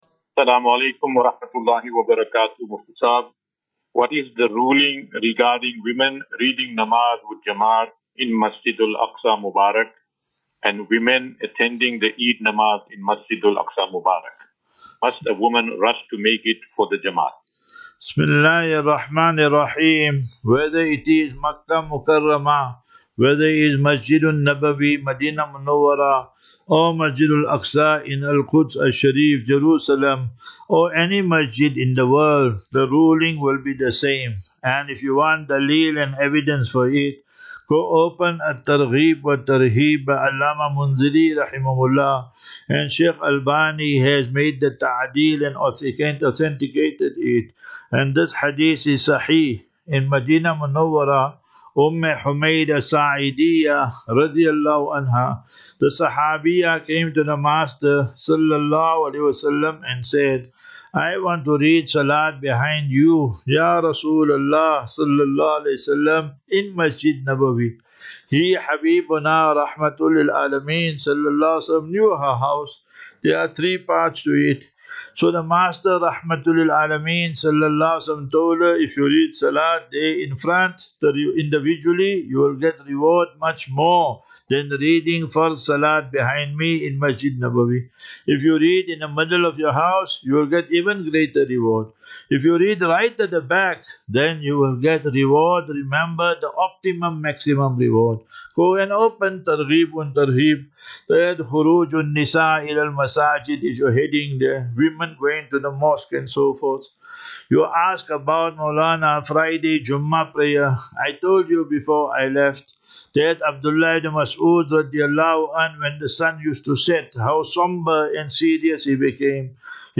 View Promo Continue Install As Safinatu Ilal Jannah Naseeha and Q and A 24 Mar 24 March 2025.